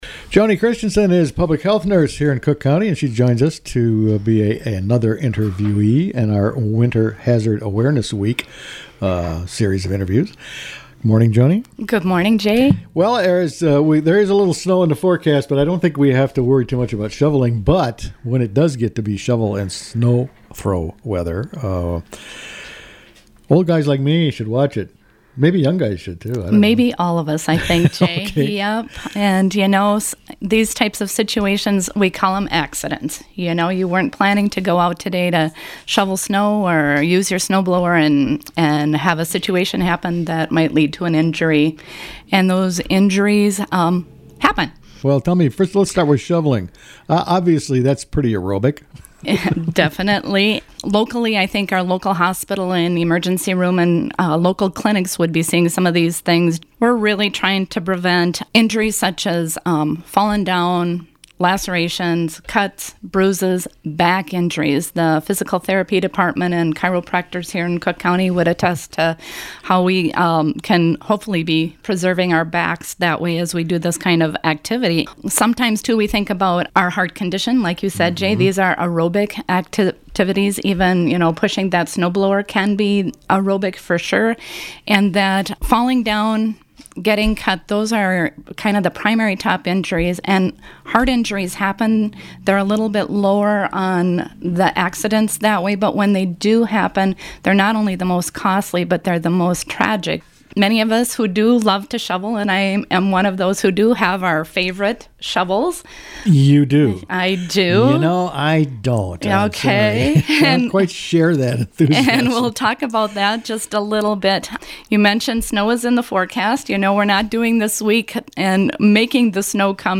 In the second of our Winter Hazard Awareness Week interviews